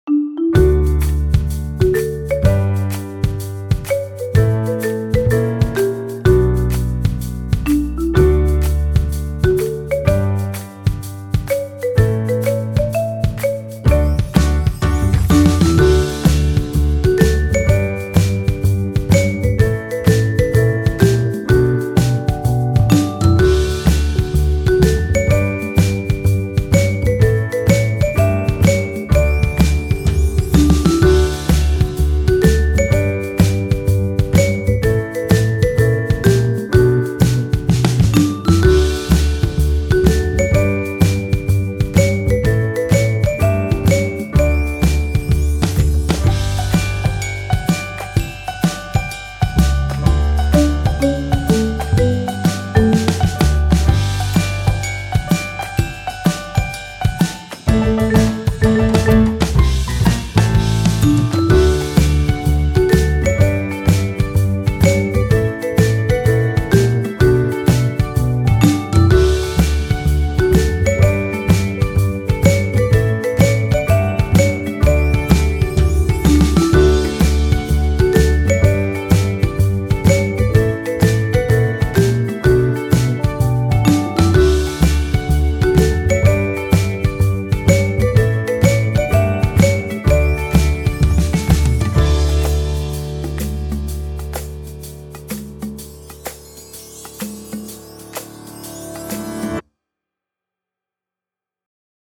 コメント はねたリズムが可愛らしいBGMです。
カテゴリー BGM素材 タグ パーカッション ピアノ ワクワク 元気 冒険 可愛い 日常 楽しい 温かい 軽快